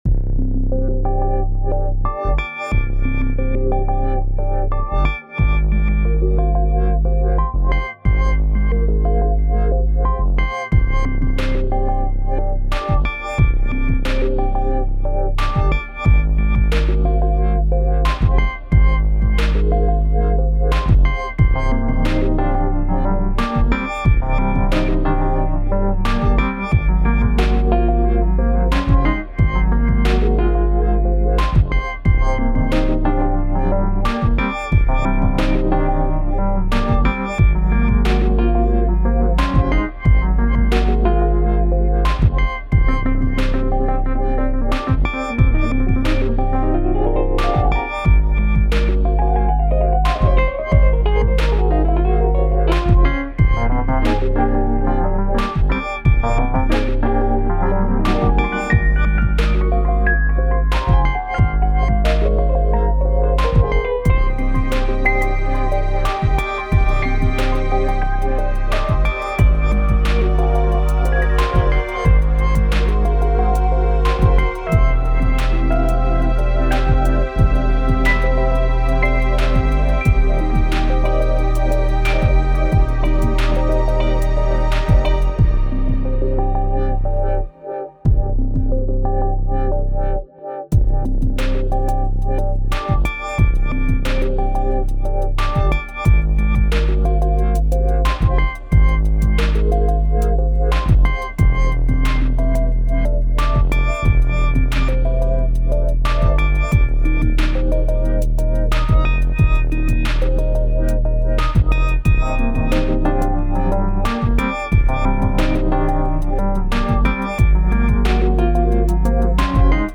Shimmering vibes groove across cool polished surfaces